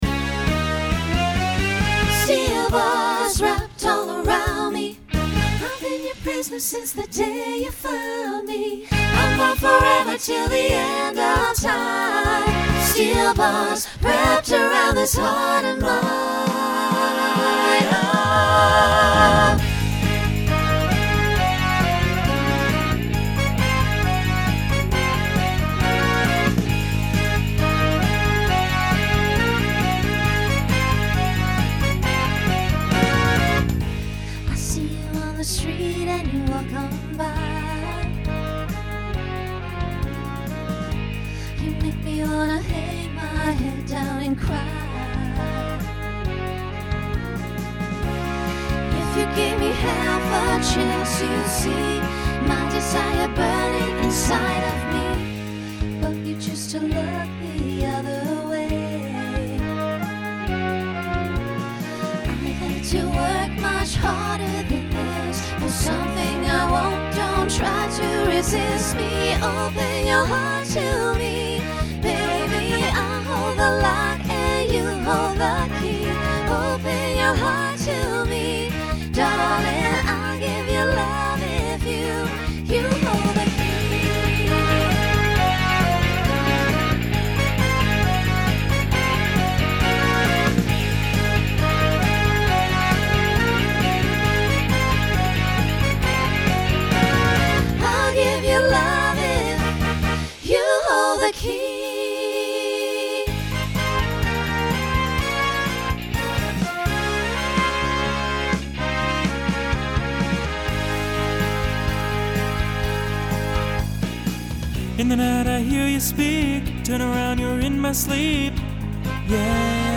SATB/SSA/TTB/SATB
Genre Pop/Dance
Transition Voicing Mixed